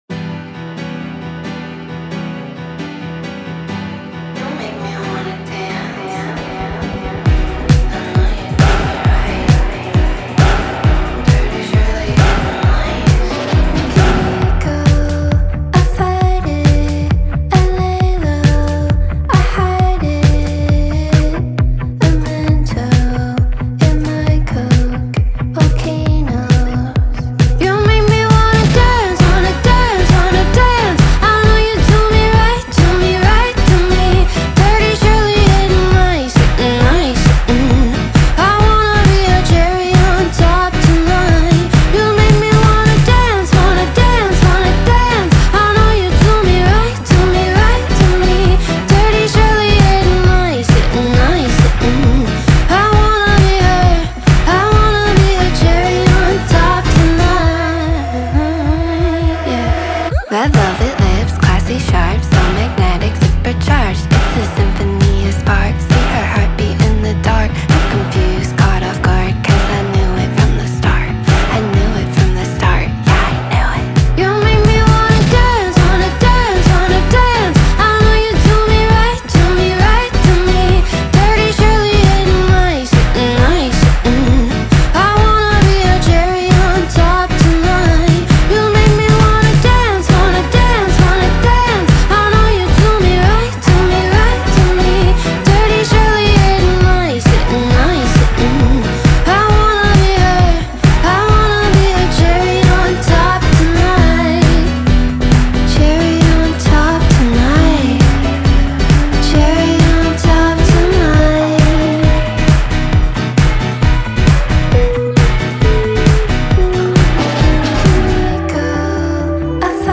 BPM134-134
Audio QualityPerfect (High Quality)
Alternative Pop song for StepMania, ITGmania, Project Outfox
Full Length Song (not arcade length cut)